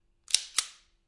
45口径手枪正在扣动扳机
描述：加载mag后，45 cal手枪翘起